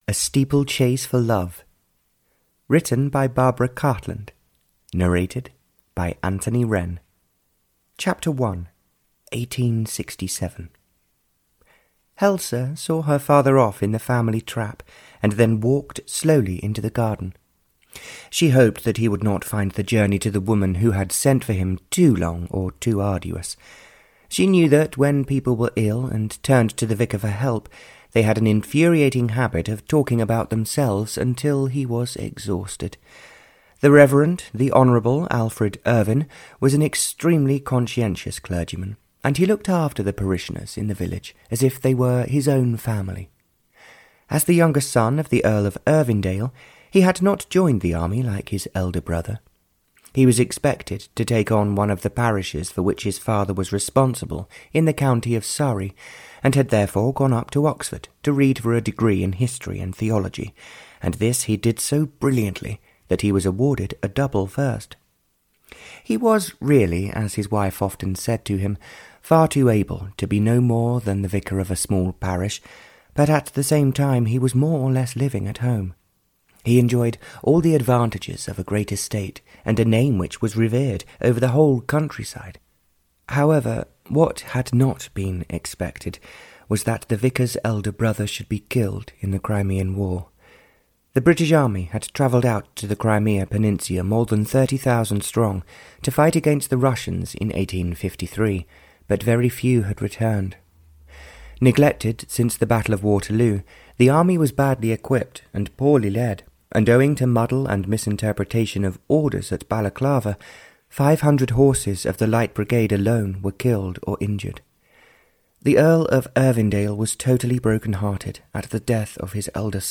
Audio knihaA Steeplechase for Love (Barbara Cartland s Pink Collection 84) (EN)
Ukázka z knihy